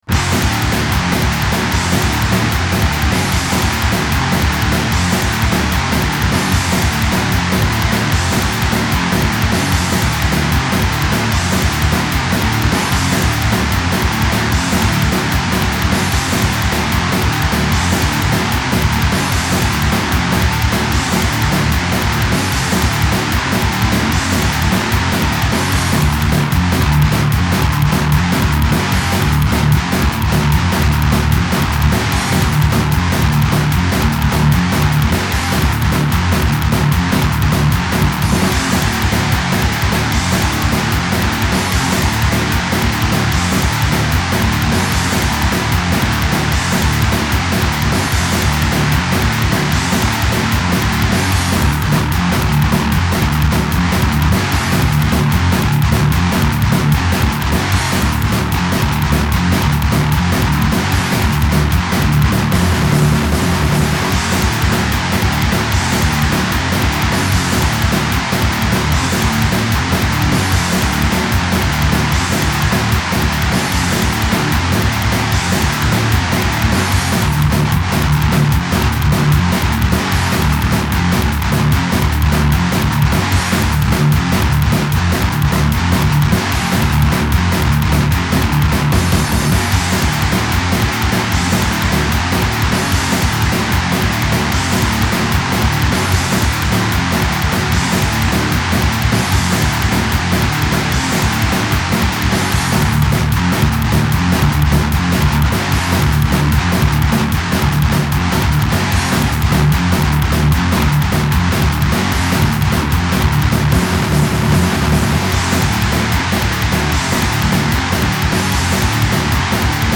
Son petit côté "Death ́n’ roll" fait que je ne l'ai pas gardé. Et puis j'avais la flemme de refaire le solo qui n’est pas très bon.
♪ Deathwing - Morceau Sans Titre Instrumental (nom de code "Carnage") ♪